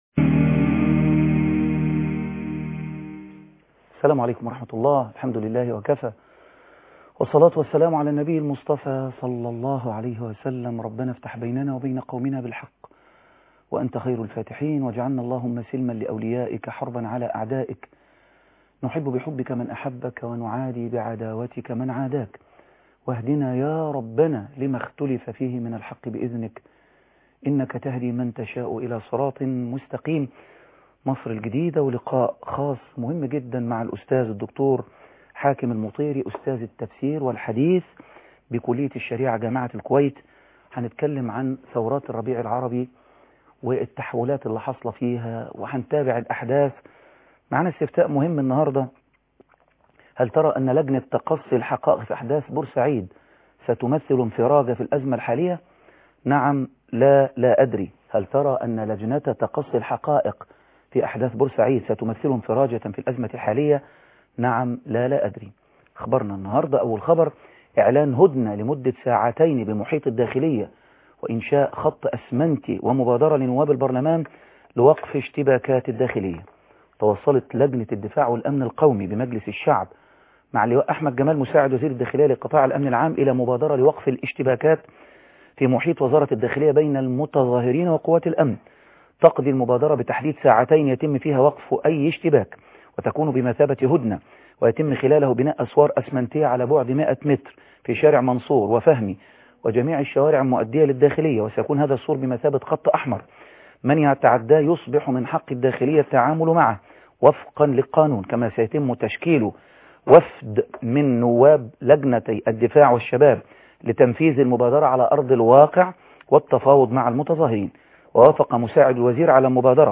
لقاء خاص مع الاستاذ الدكتور حاكم مطيرى ( 4/2/2012 ) مصر الجديدة - الشيخ خالد عبد الله